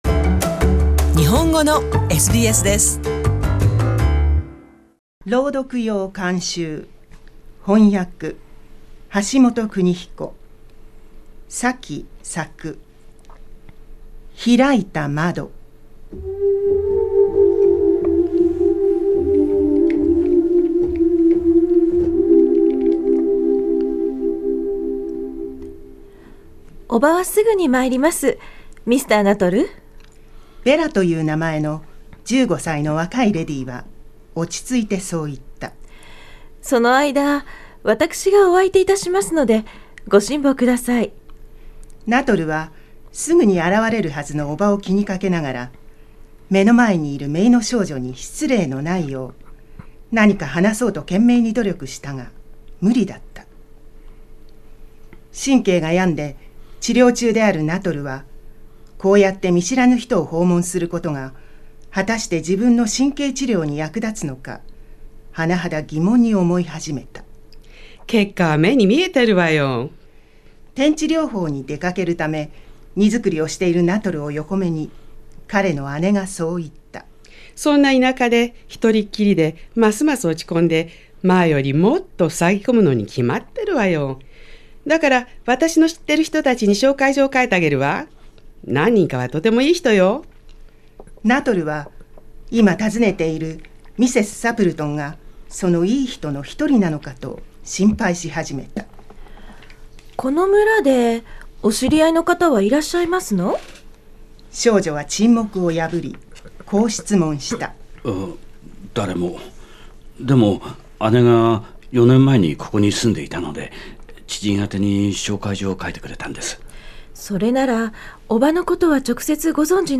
シドニーで活動する朗読の会「声」が、英国の作家・サキの短編小説「開いた窓」をお送りします。